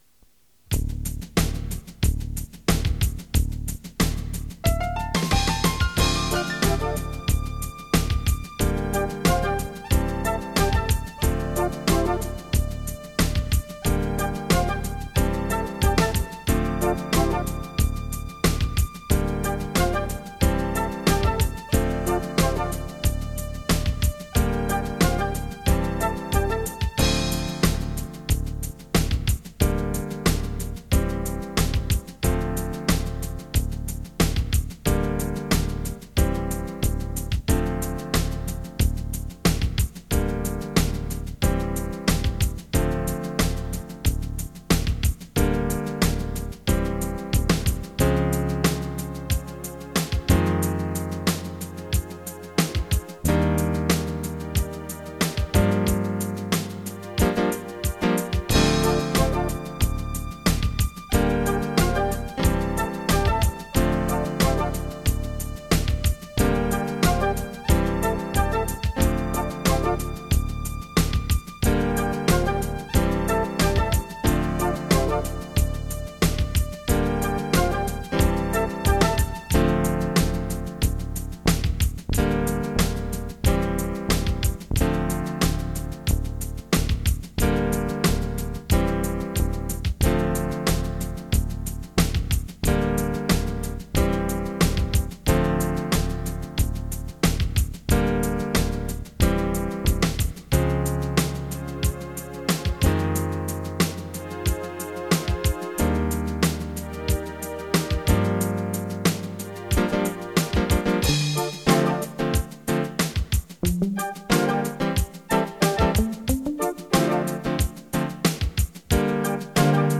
he had a career as a RolandD20 song writer.